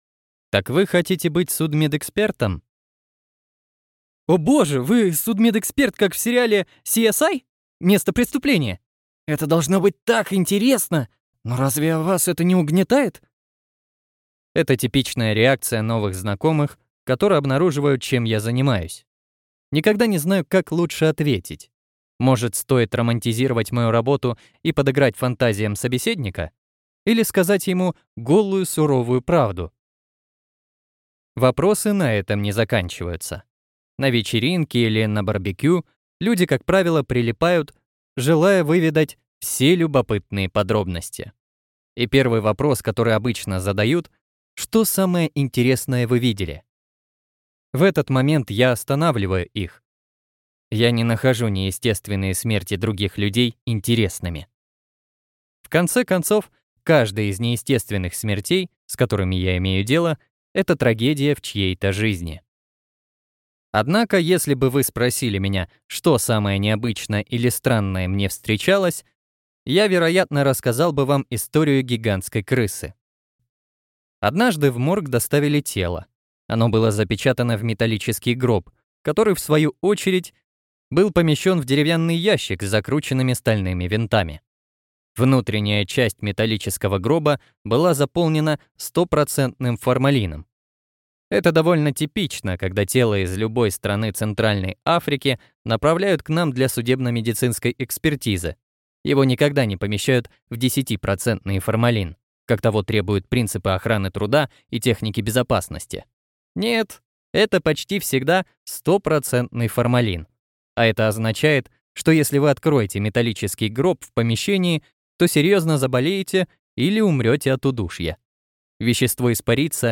Аудиокнига Вскрытие. Суровые будни судебно-медицинского эксперта в Африке | Библиотека аудиокниг